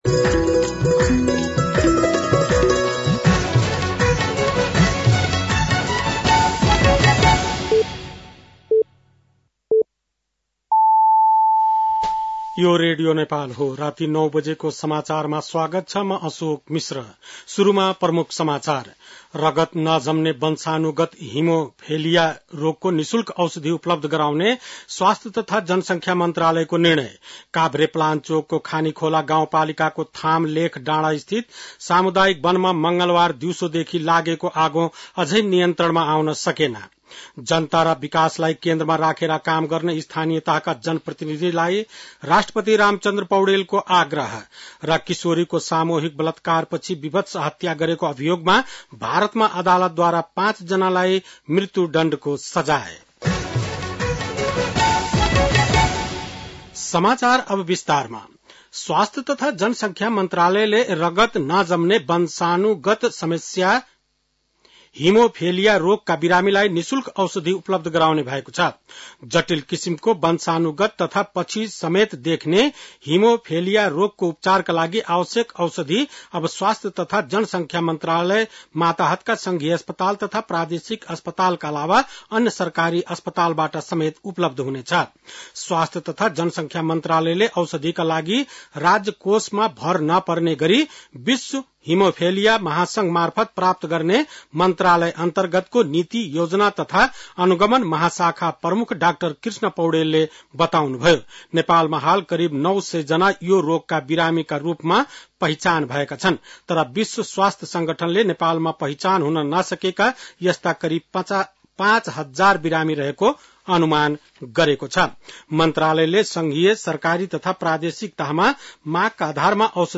बेलुकी ९ बजेको नेपाली समाचार : ११ माघ , २०८१
9-PM-Nepali-NEWS-10-10.mp3